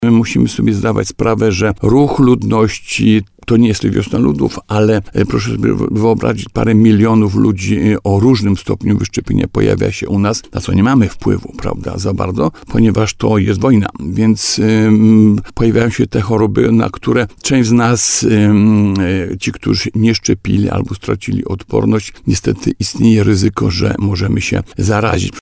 Takie choroby jak błonica mogą pojawiać się coraz częściej [ROZMOWA]